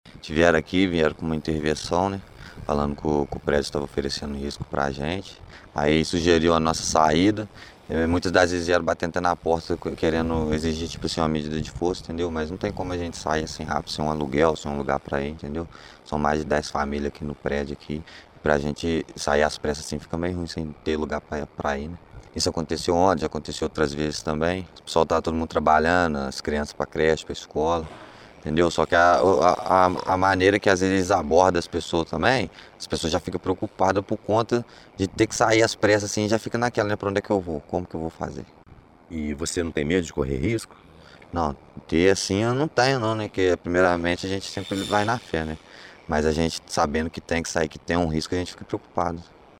A fonte, que pediu para não ser identificada e teve a voz modificada, explicou como foi realizada a intervenção.
morador do edifício